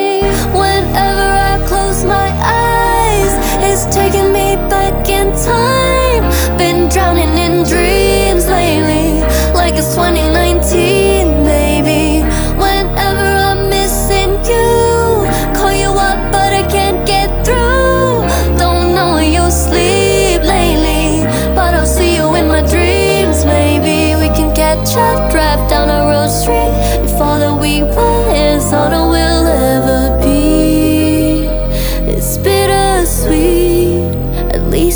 K-Pop
2025-02-28 Жанр: Поп музыка Длительность